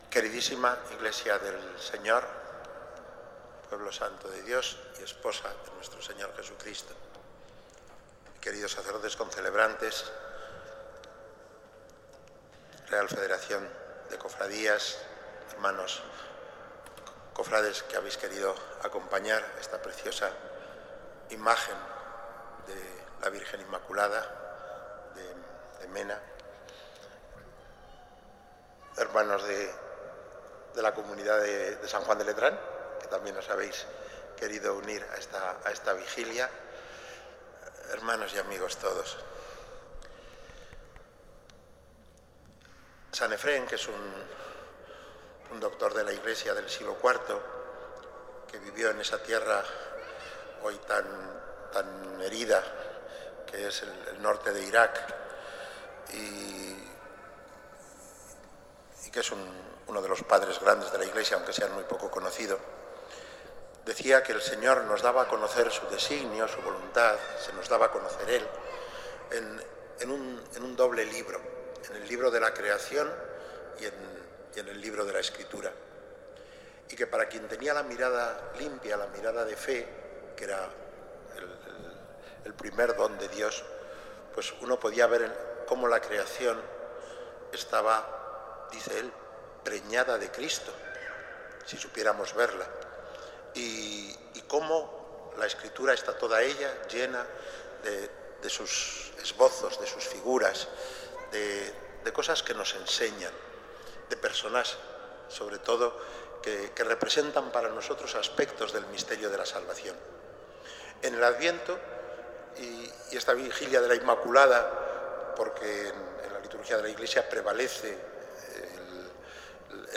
Homila._Vigilia_Inmaculada._7-12-14.mp3